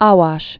wäsh) also Ha·wash (hä-)